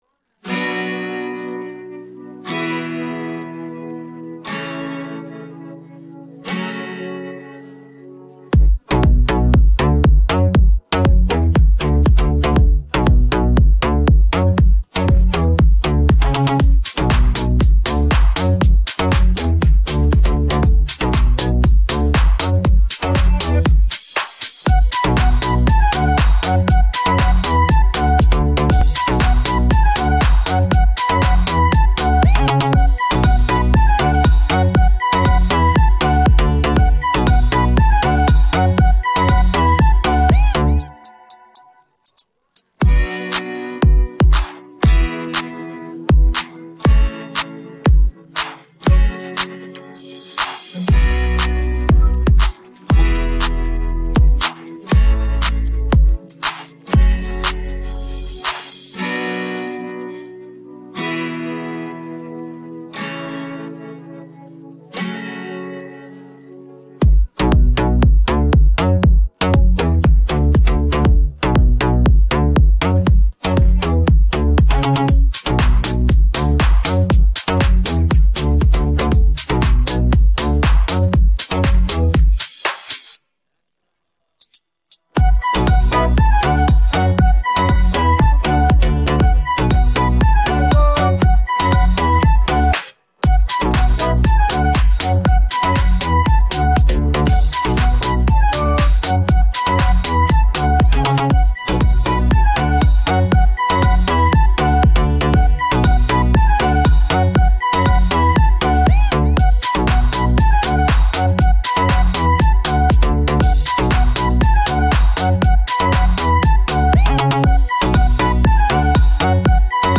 Українські хіти караоке Описание